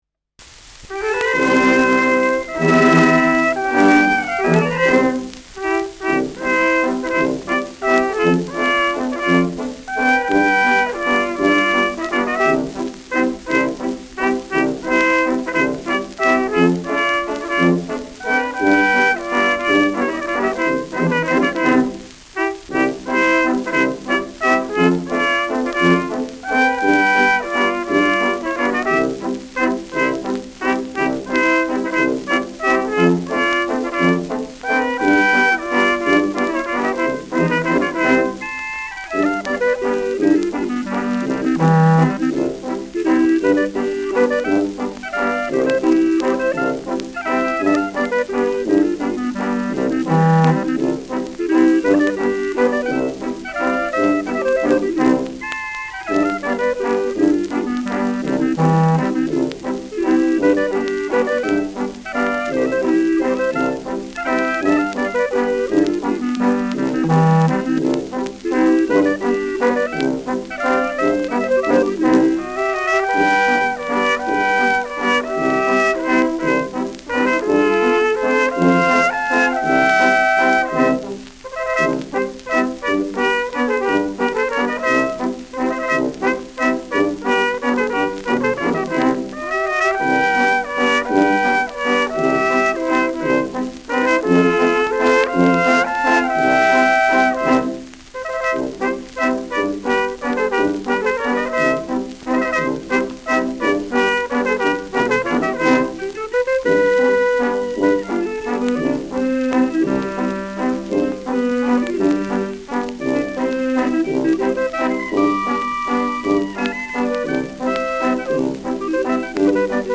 Schellackplatte
leichtes Rauschen : leichtes Knistern : leichtes Leiern
Anders als auf dem Etikett vermerkt, ist bei dieser Aufnahme keine Harmonika zu hören, dafür aber Streicher-Nachschlag.
[München?] (Aufnahmeort)